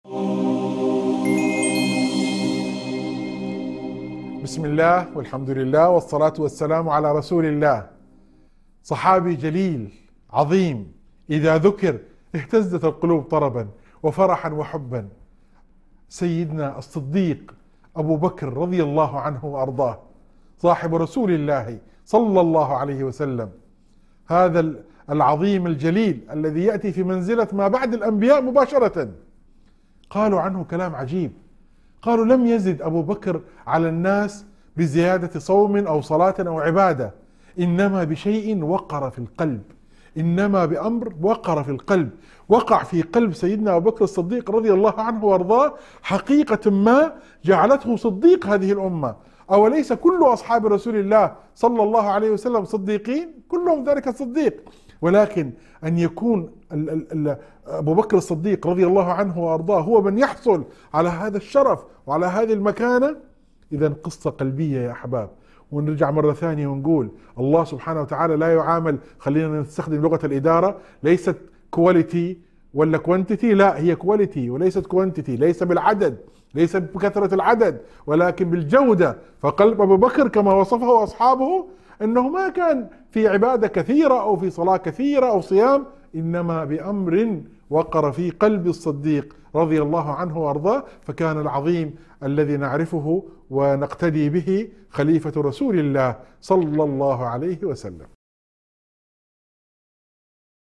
حديث مؤثر عن منزلة سيدنا أبي بكر الصديق رضي الله عنه، يسلط الضوء على سر تفوقه وإيمانه العميق الذي تمثل في قلبه لا في كثرة العبادات فقط.